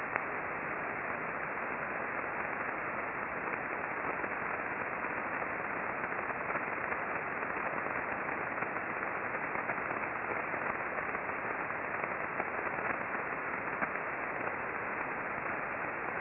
We used two Icom R-75 HF Receivers, one tuned to 19.556 MHz (LSB), corresponding to the Red trace in the charts below, and the other tuned to 20.903 MHz (LSB), corresponding to the Green trace.
We observed mostly S-bursts (popping sound) during the periods mentioned above.